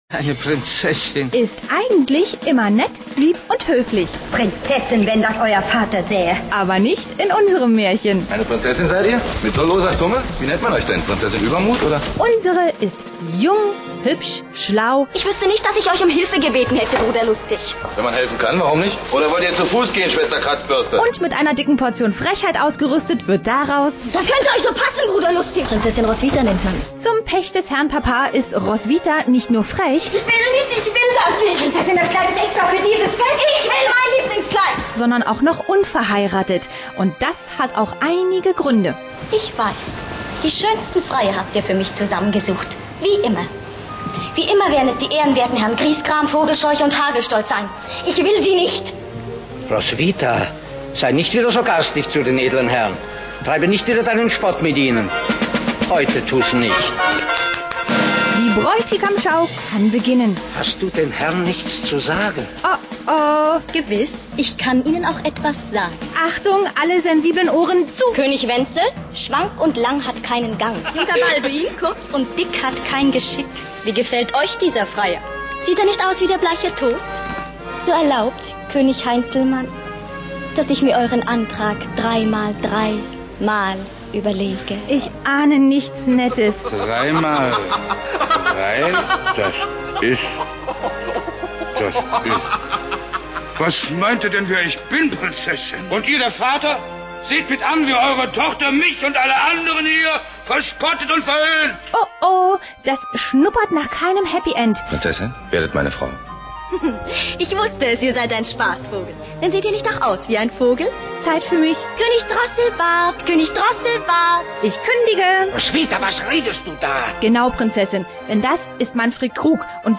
Audio-Trailer